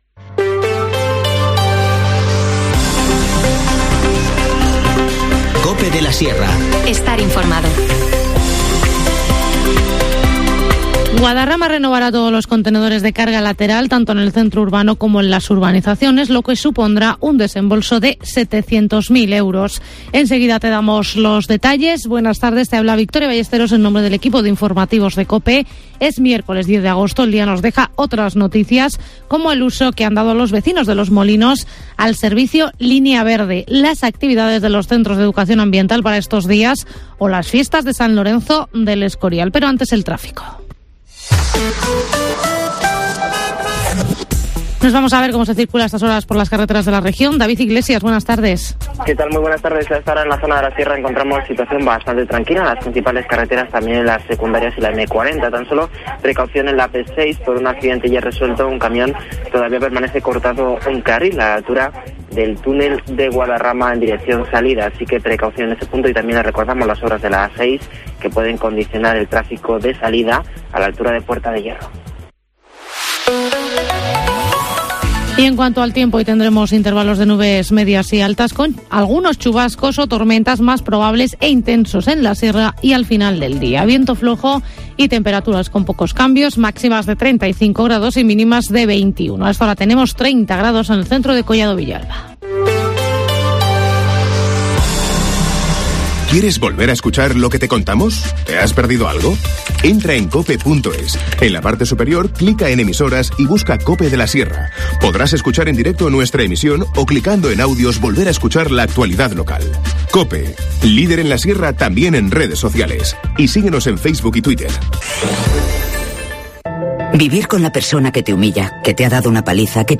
Informativo Mediodía 10 agosto